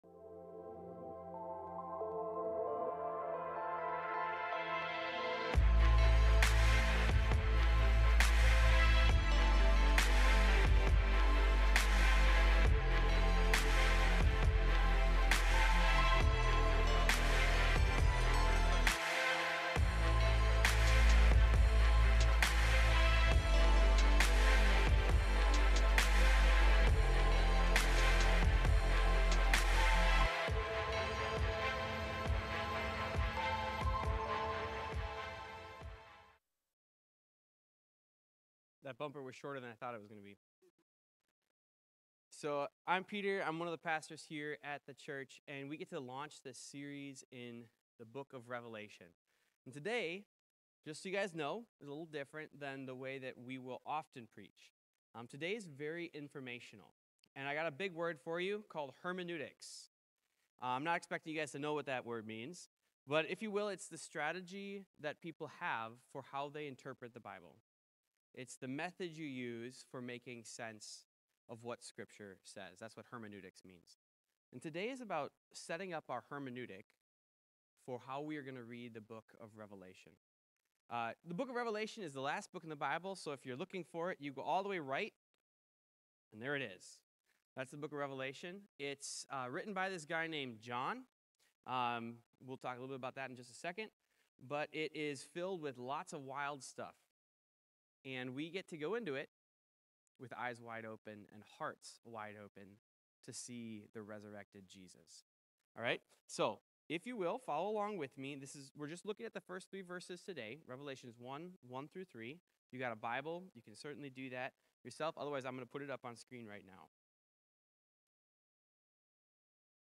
Revelation | Sermon 4.12.26
Revelation-Sermon-4.12.26.m4a